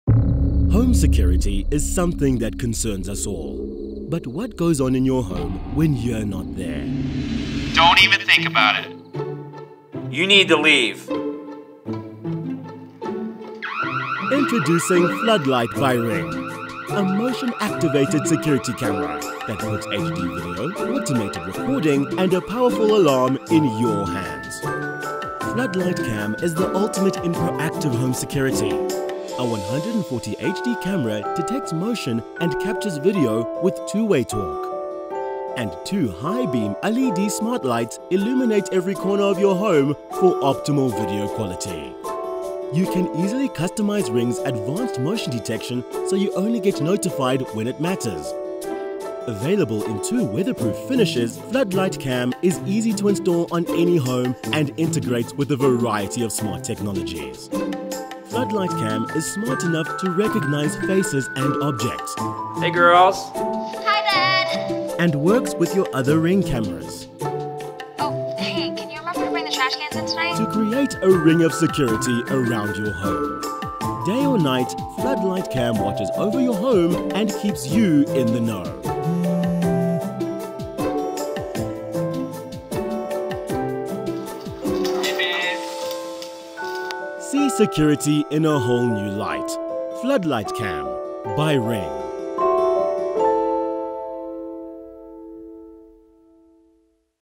Englisch (südafrikanisch)
- Warme, dynamische und professionelle Stimme
- Hochwertige Aufnahmen aus meinem modernen Homestudio
Audio Technica AT2020 Nieren-Kondensatormikrofon